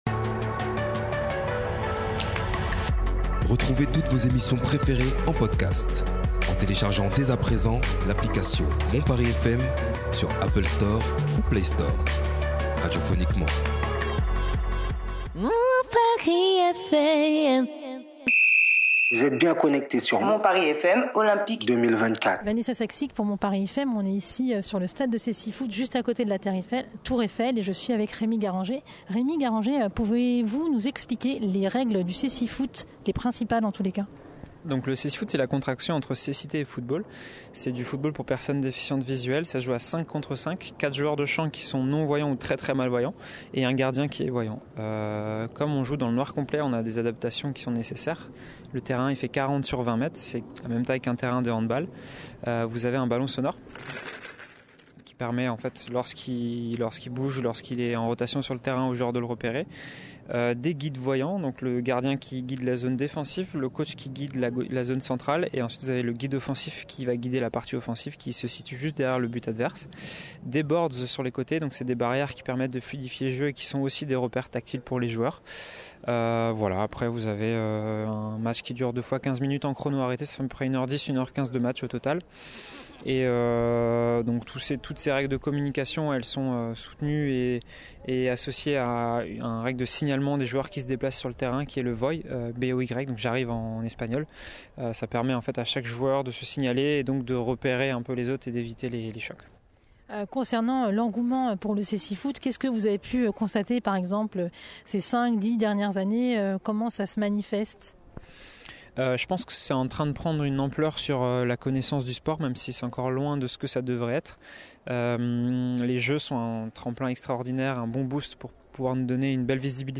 sur le futur terrain paralympique au pied de la Tour Eiffel